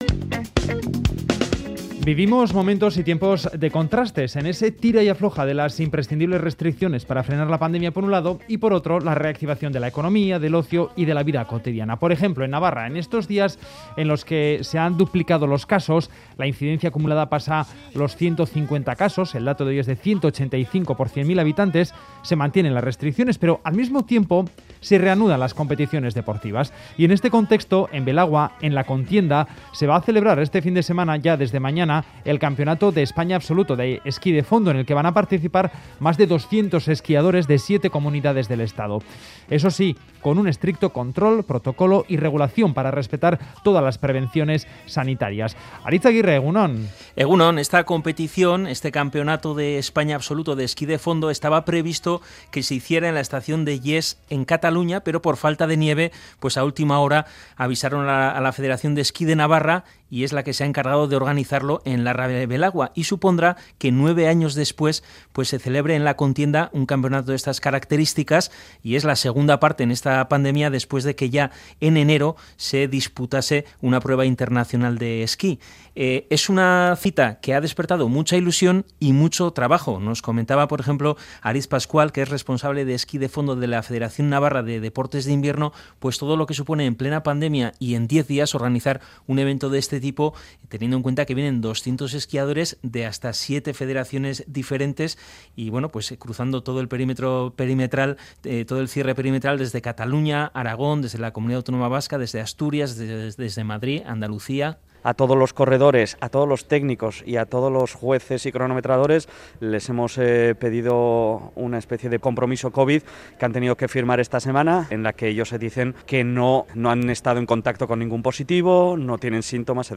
ha ido al Valle de Belagua en Navarra